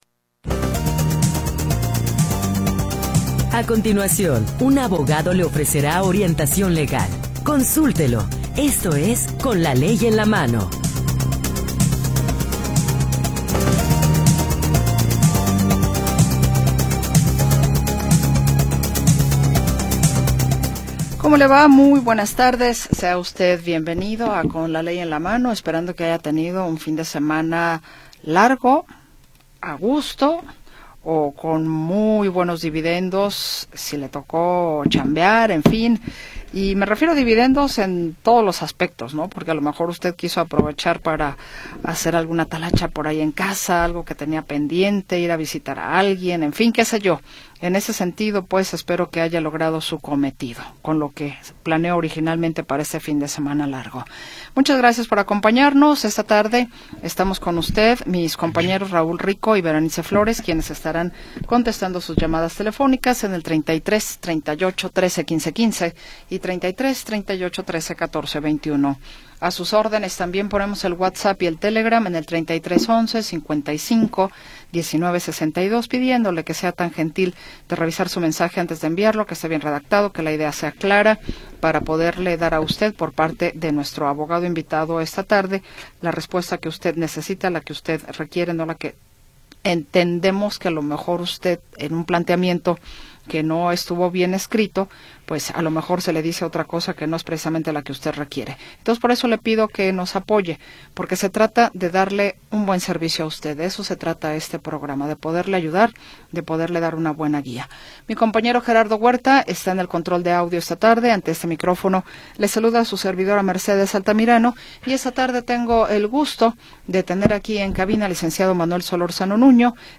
Orientación legal de jueces y abogados especialistas